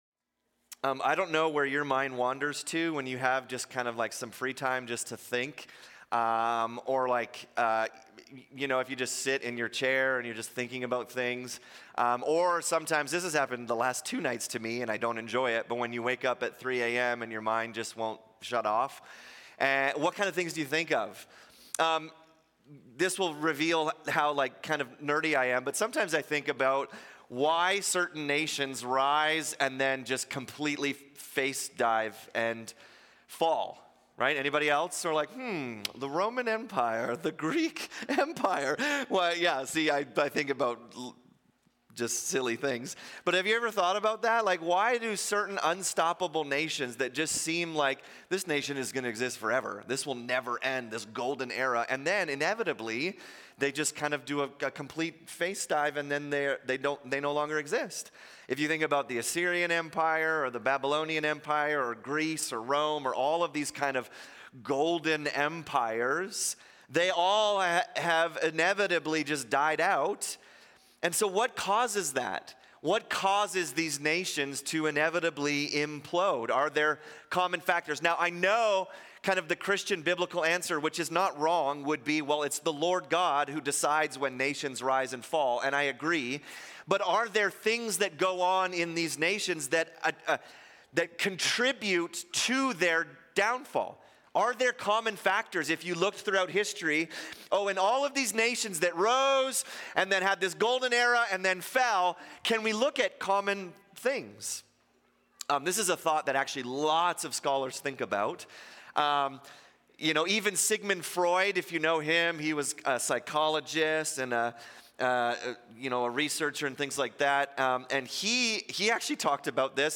In this sermon we look at the 5th commandment: honour your father and mother. What does it mean to honour someone? How do we obey this commandment?